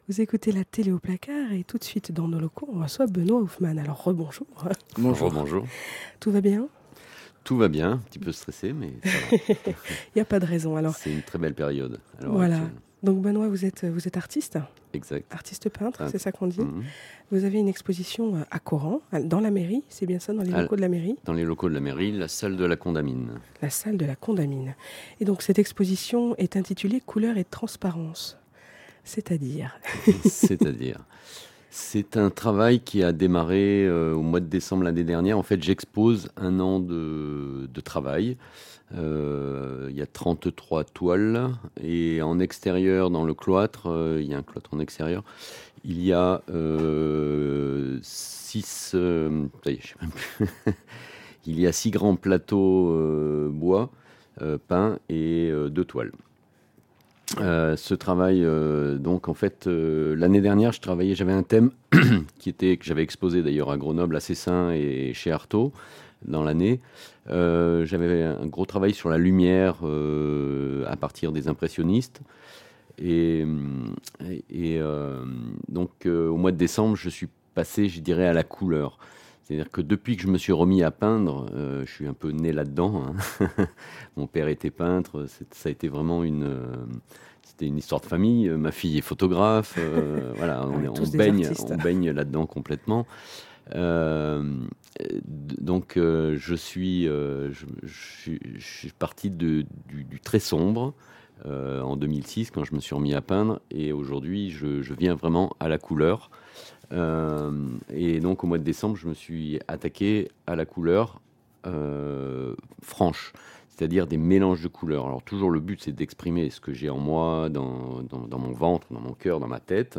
Interview de 10mn pour parler de l’expo de Corenc à la Condamine cliquer sur le bouton vert à gauche